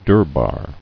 [dur·bar]